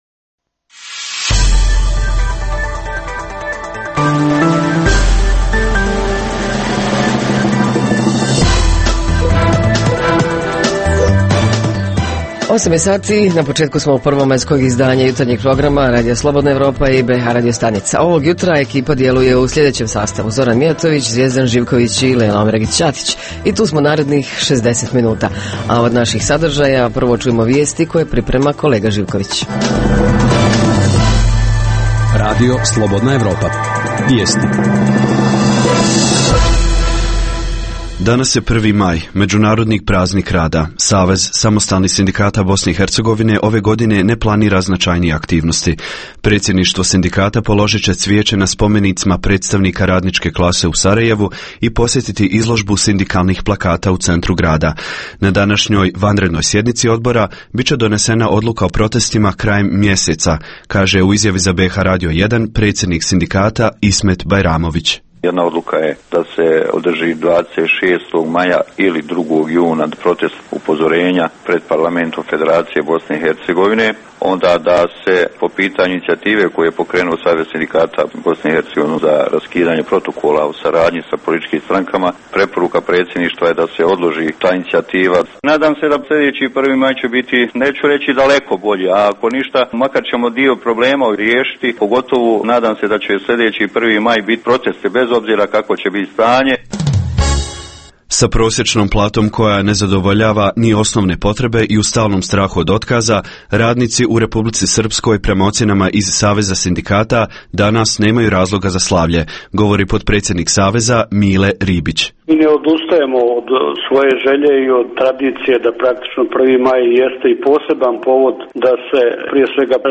Tema jutra: Praznik rada, 1. Maj – kako će biti obilježen u bh gradovima? Reporteri iz cijele BiH javljaju o najaktuelnijim događajima u njihovim sredinama.
Redovni sadržaji jutarnjeg programa za BiH su i vijesti i muzika.